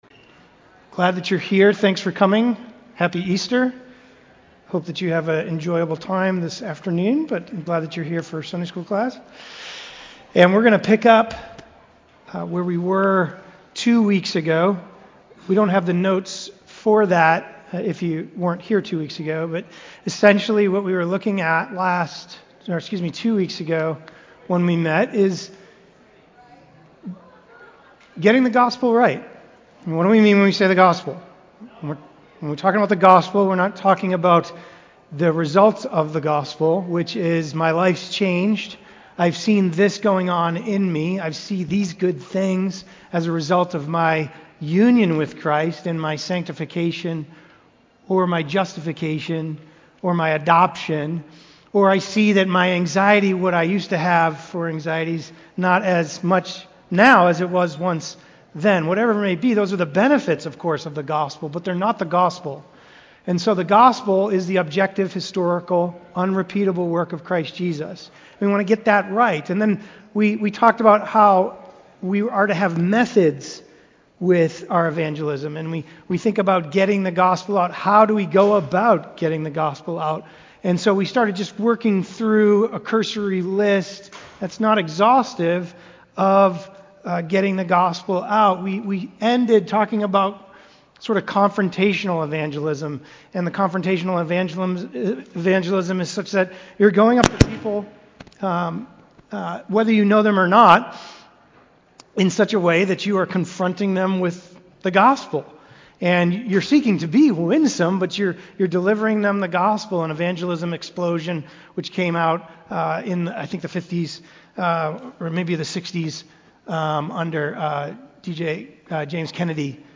Sunday School Classes